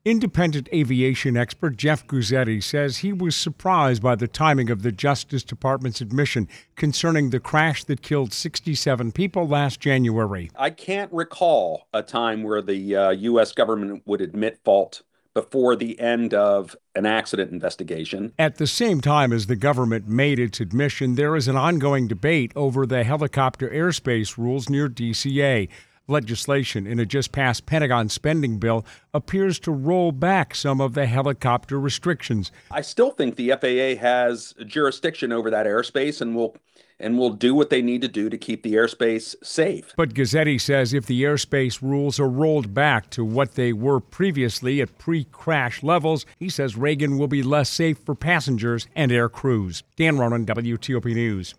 speaks with aviation safety professional